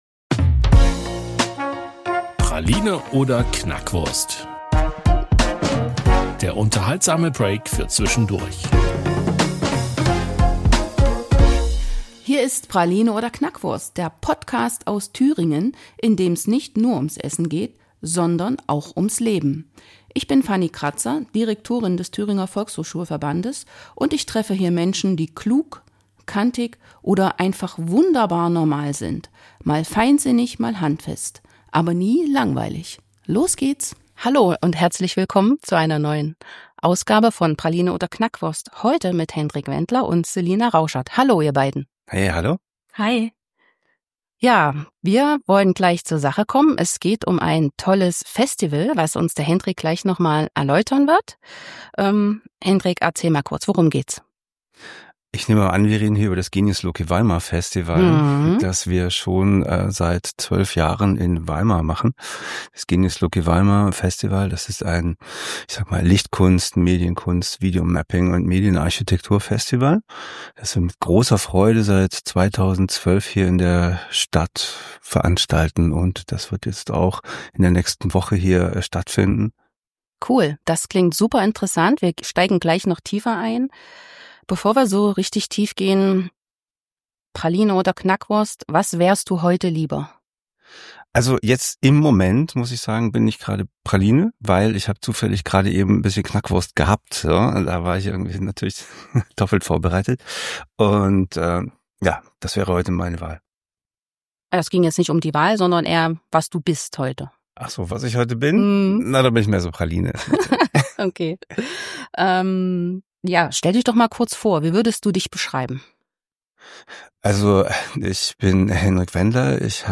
Gemeinsam tauchen sie ein in die Welt der Lichtkunst, in der Architektur zur Leinwand wird und Projektionen ganze Städte ins Leuchten versetzen. Ein Gespräch über die Entstehung des Festivals, die Magie von Videomapping im öffentlichen Raum – und darüber, wie Licht nicht nur Mauern verwandeln, sondern auch Menschen berühren kann.